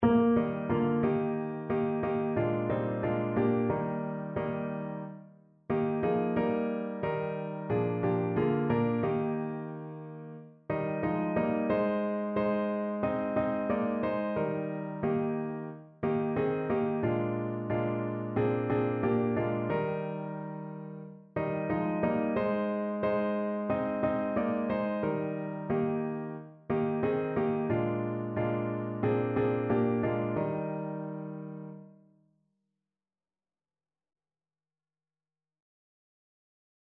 Notensatz (4 Stimmen gemischt)
Satz: Frank Ulrich